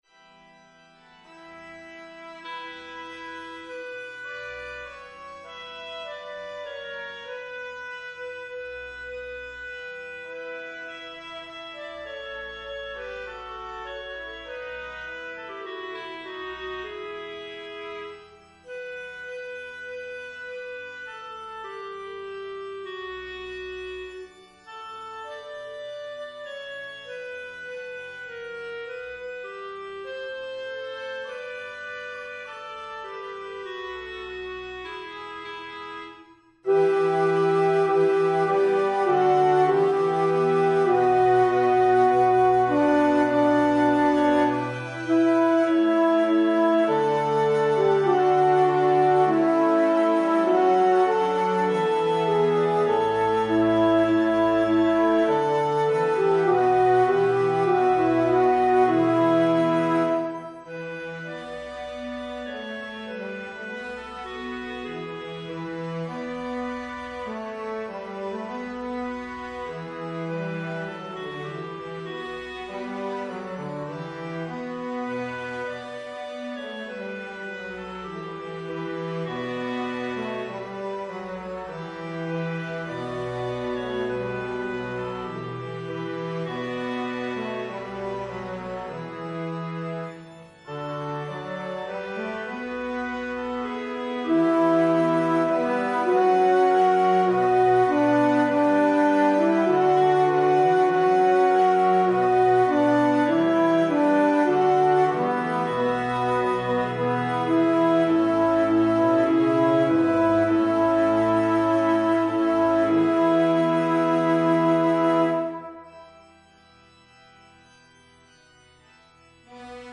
Alto
Evensong Setting